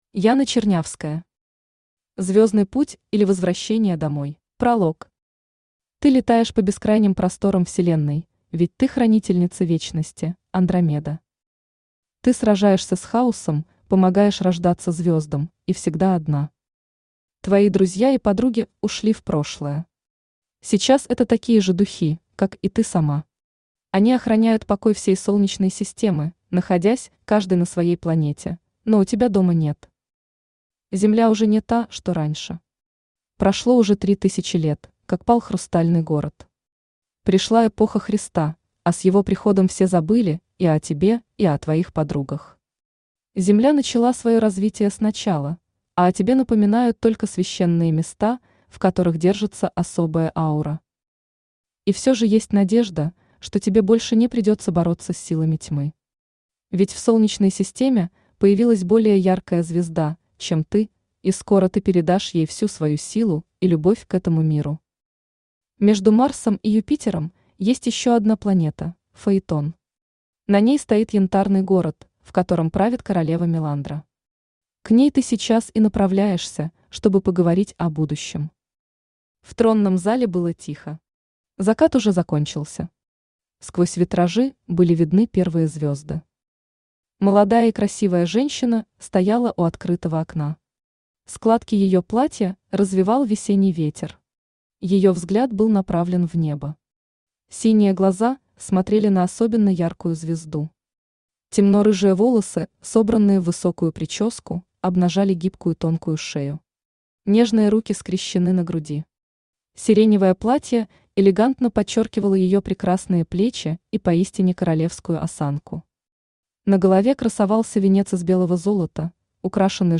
Aудиокнига Звёздный путь или Возвращение домой Автор Яна Чернявская Читает аудиокнигу Авточтец ЛитРес.